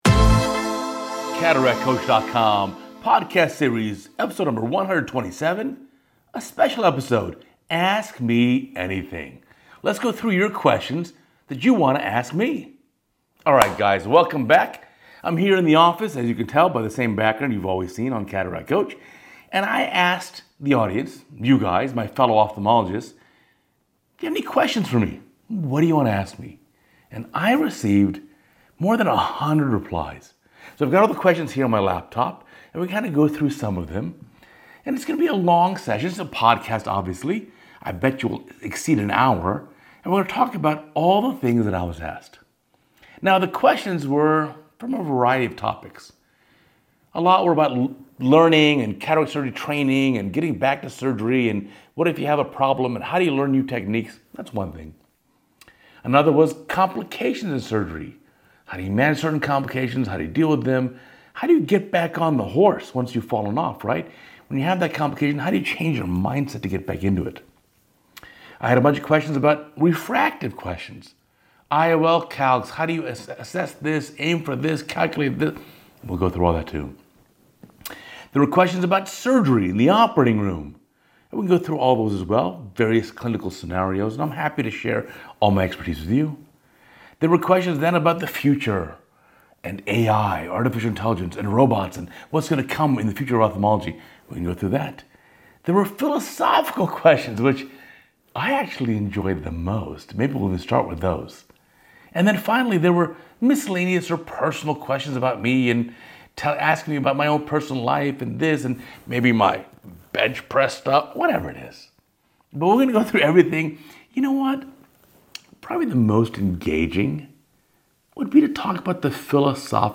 The topics cover the range from philosophical to personal to surgical to learning. To make this as real as possible there are no edits in this video -- it is me talking straight for an hour so feel free to hit the 1.5x or 2x button on your player!